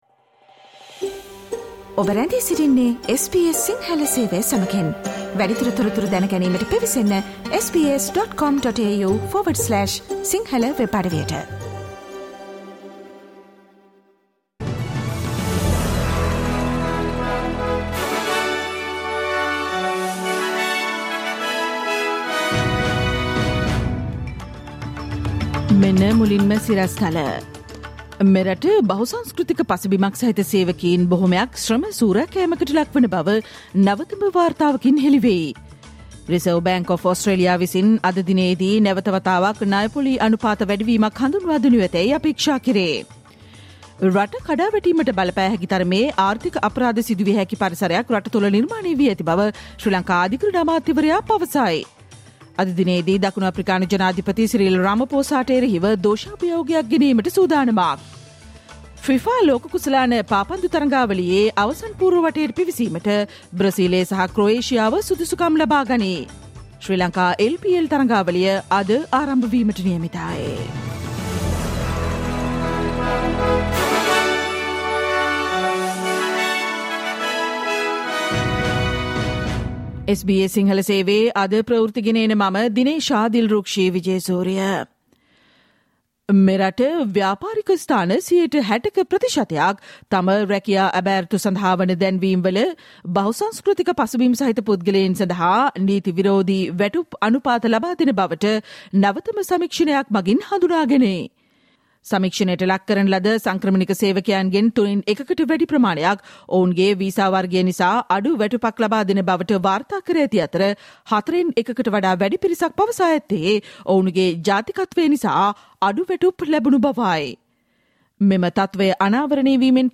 Listen to the SBS Sinhala Radio daily news bulletin on Tuesday 06 December 2022